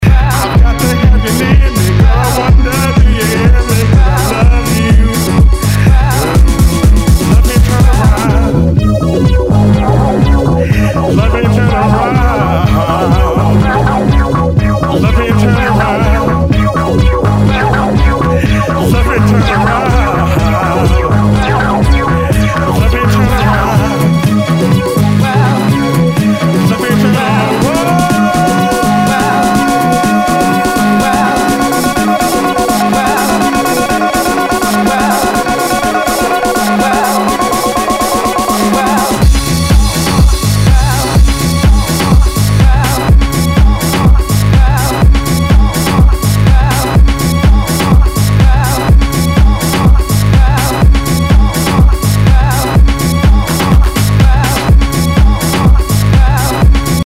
HOUSE/TECHNO/ELECTRO
ナイス！ファンキー・ハウス！